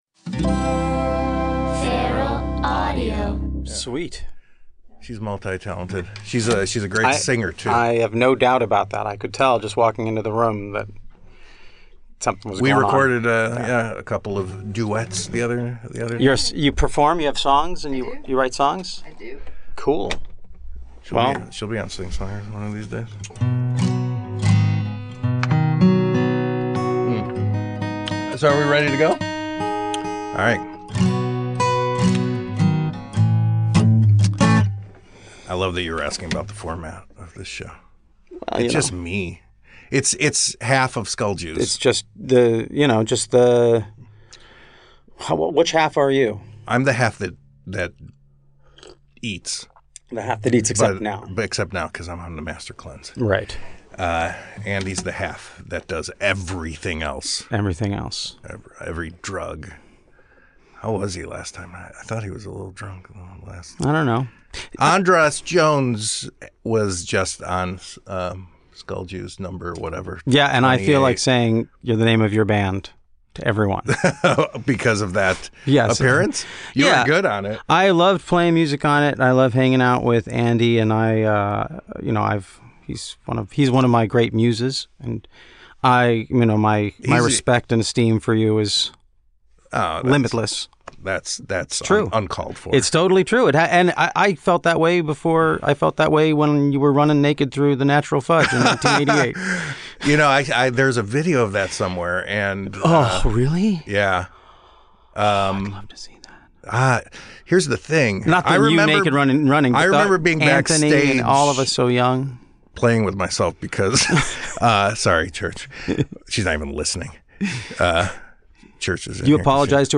write a song on the spot
and then he plays some originals.